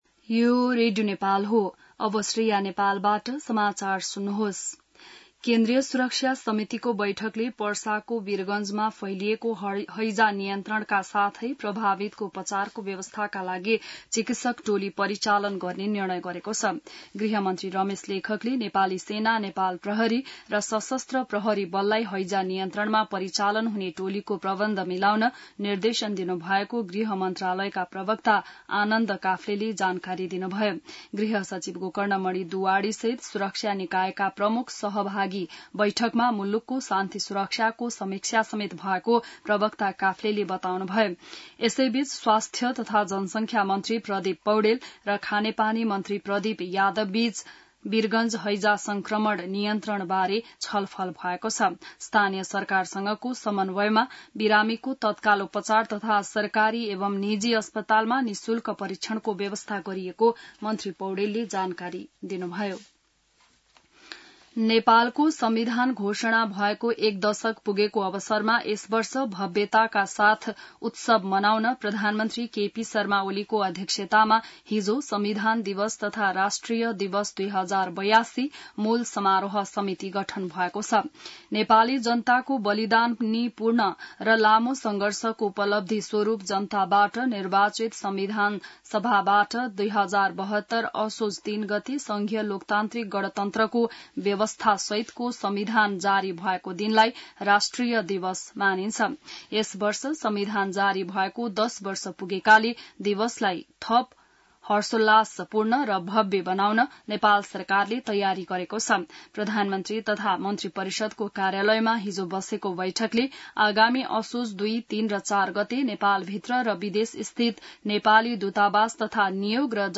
बिहान ६ बजेको नेपाली समाचार : ९ भदौ , २०८२